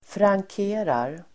Uttal: [frangk'e:rar]